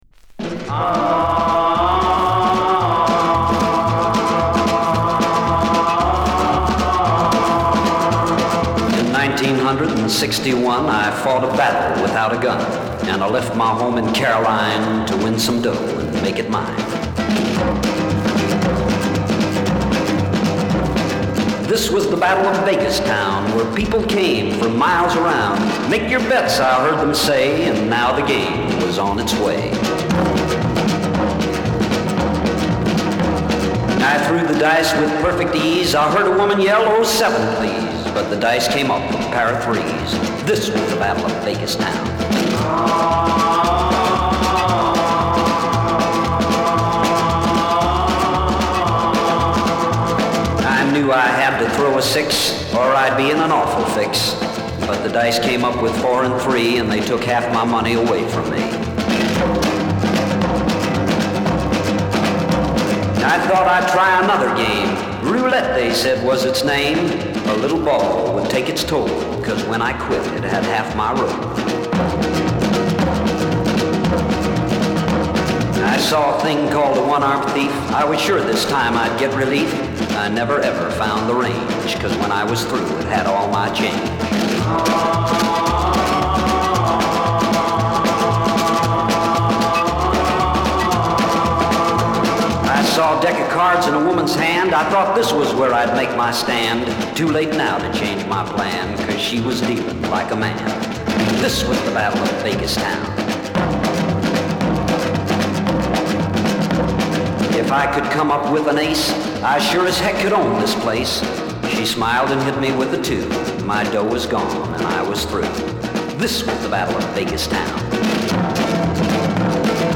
アラビアンナイトを題材としたコミカルなノヴェルティ・ロッカー。
[Exotica] [NEW]